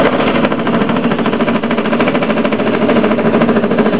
Tue Mar 02 12:27 2004 "z�taras" Tue Mar 02 12:25 2004 pohotov� ob�erstven� Tue Mar 02 13:17 2004 Jedeme do kopce A tento zvuk vyd�v� n� autobus ...